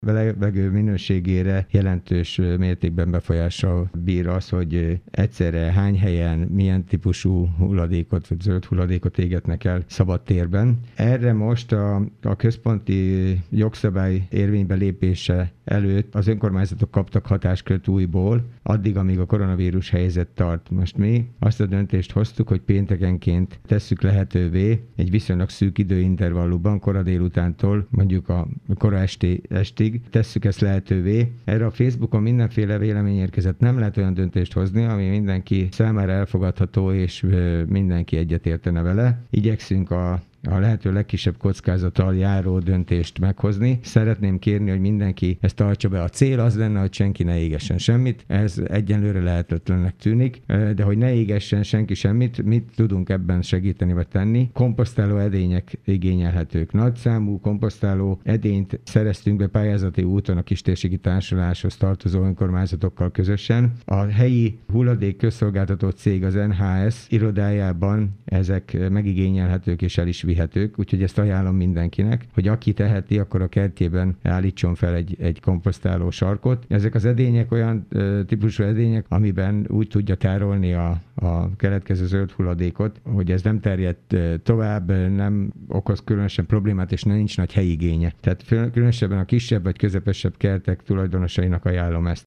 Pénteken délután lehet kerti hulladékot égetni Dabason. A végső cél az égetés megszüntetése lenne, ezért komposztáló edényt lehet igényelni Dabason. Kőszegi Zoltán polgármestert hallják.